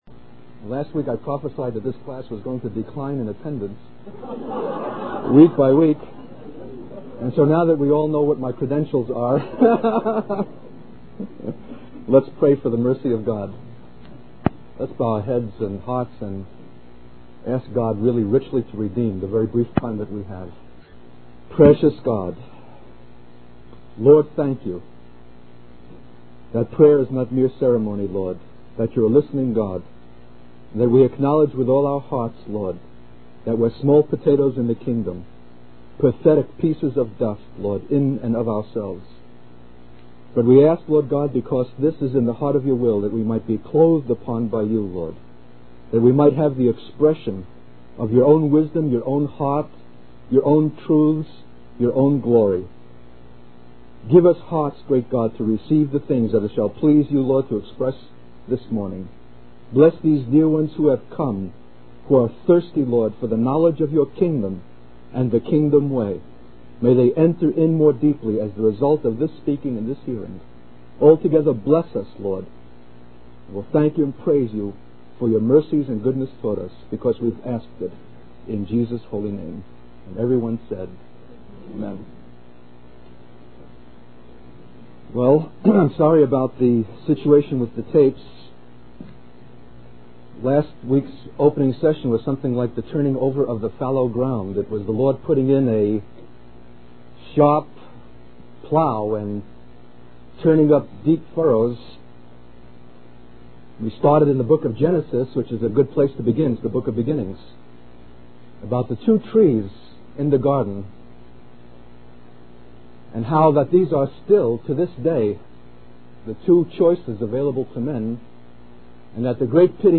In this sermon, the speaker reflects on a personal experience where they shared their testimony and were met with scorn and anger from their former friends.